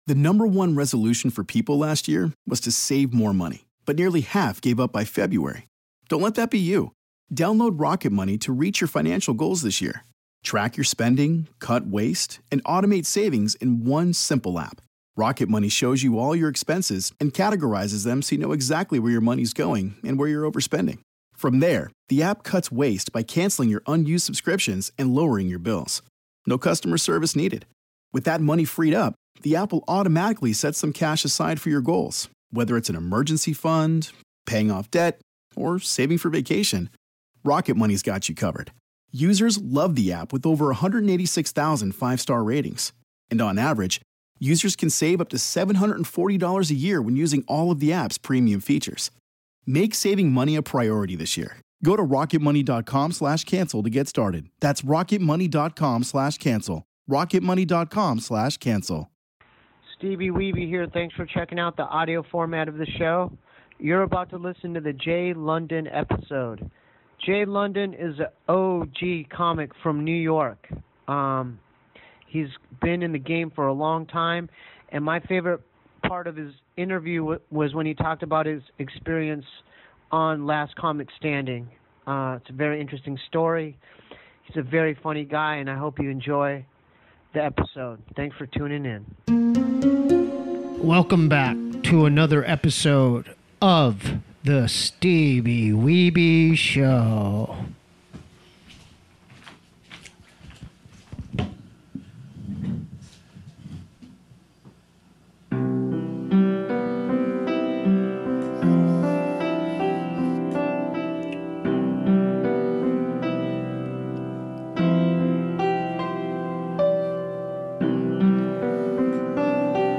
Comedy, Arts, Spirituality, Religion & Spirituality, Visual Arts